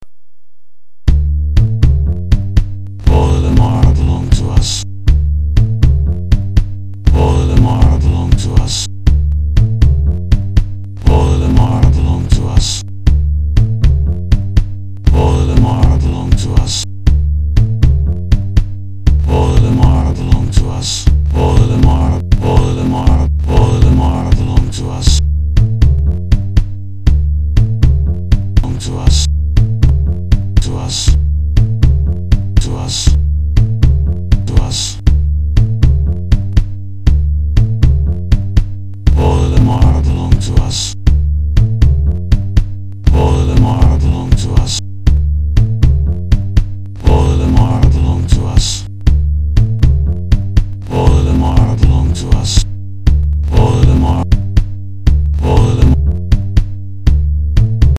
part 2 is actually somewhat rhythmic.
The voice is a bit random. Not very rhythmic.
I quite liked the very English accent.